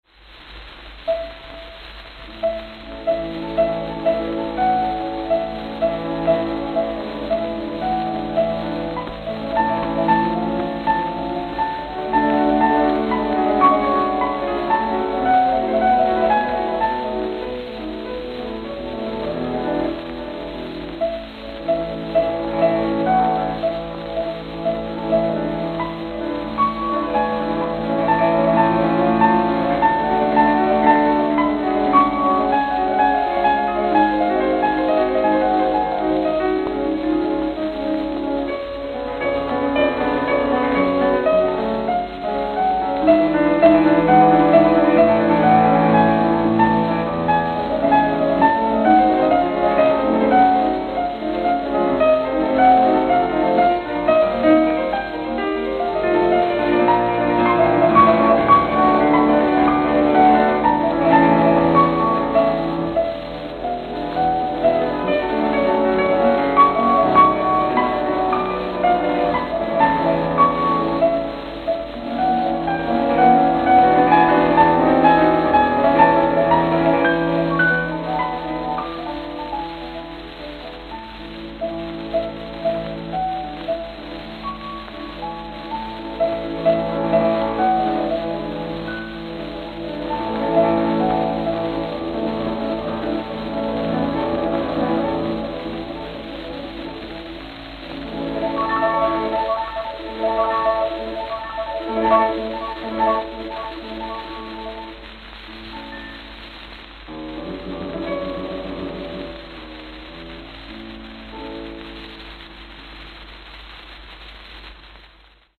This page covers electrically-recorded Red Seals from 1925 to 1933.
Camden, New Jersey Camden, New Jersey
Note: Worn. Distortion from being a very early electrical.